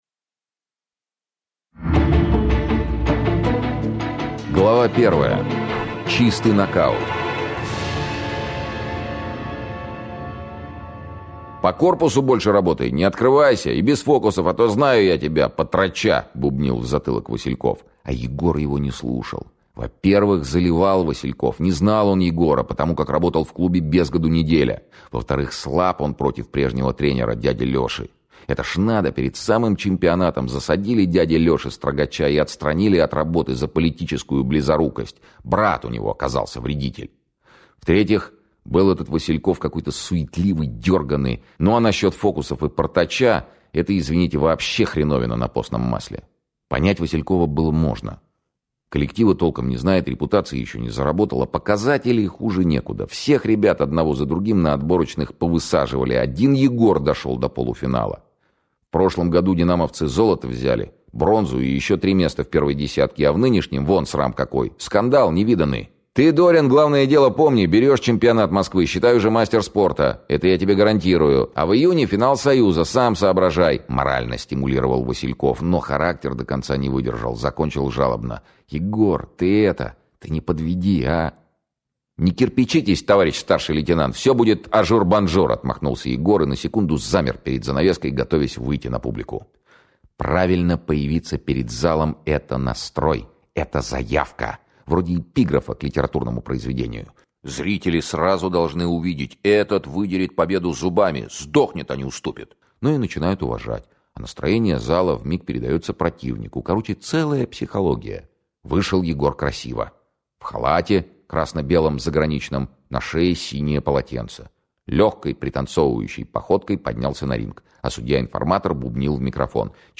Аудиокнига Шпионский роман - купить, скачать и слушать онлайн | КнигоПоиск